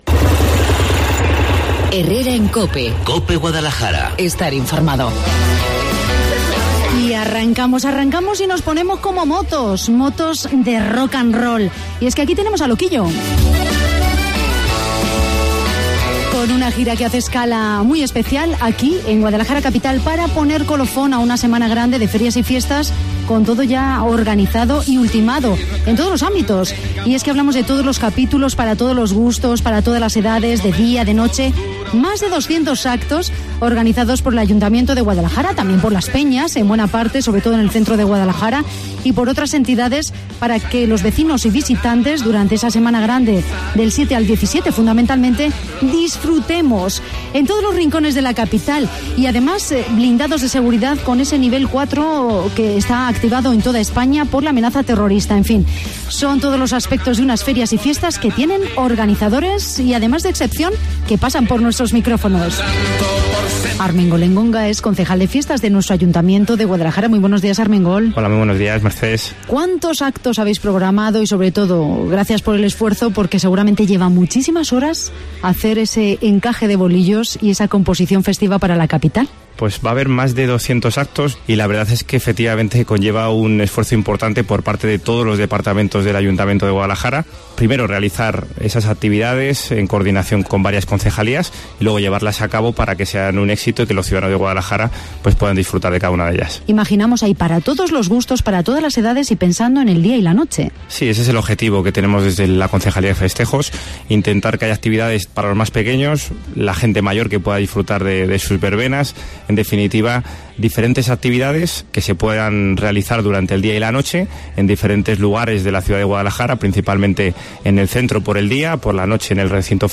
El concejal de Festejos, Armengol Engonga, nos adelanta los actos más destacados y de mayor poder de convocatoria de público de la Semana Grande de la capital, que, además, estarán blindados de seguridad en consonancia con el nivel 4 por amenaza terrorista que está activado en todo el país.